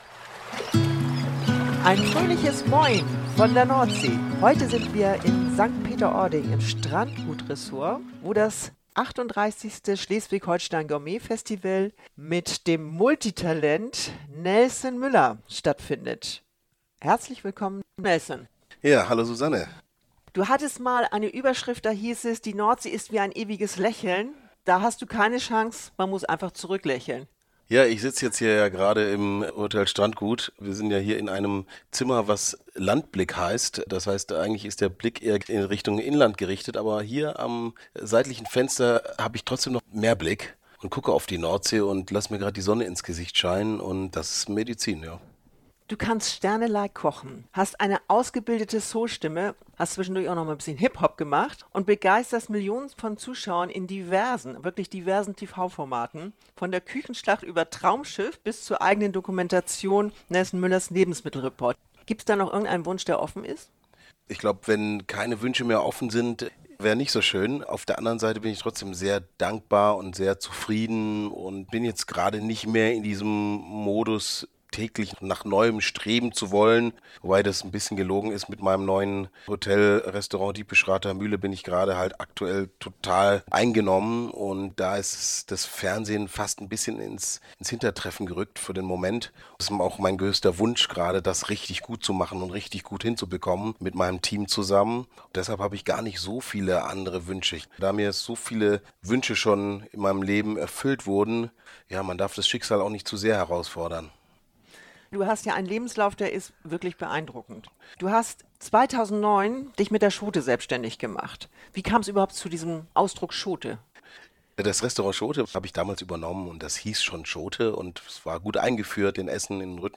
Der Podcast vom Schleswig-Holstein Gourmet Festival stellt die Gastköchinnen und Gastköche sowie die Gastgeber, Mitgliedshäuser und Partner in bunter Reihenfolge vor. Durch die lockeren Gespräche erfahren die Hörer mehr über die Persönlichkeiten, deren Gerichte, Produkte und Küchenstile.